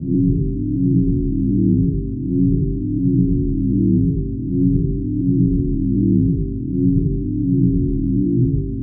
power-hum.ogg